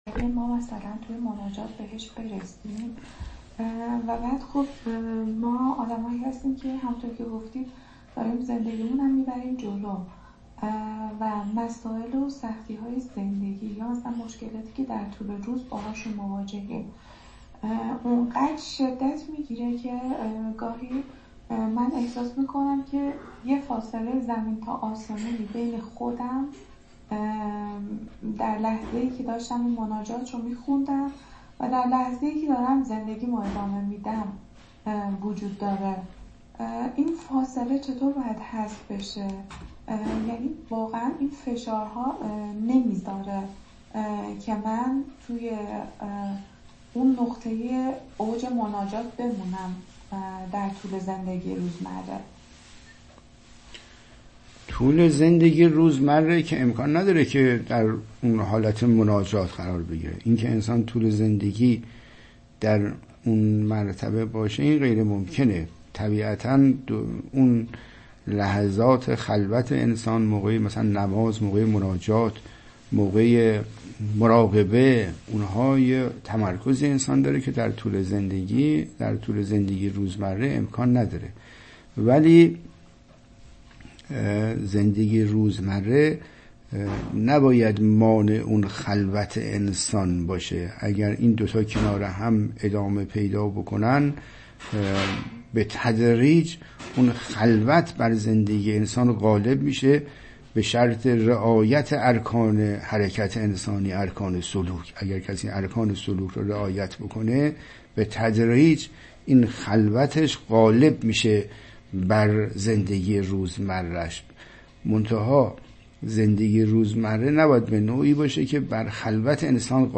متن : گفت‌وگو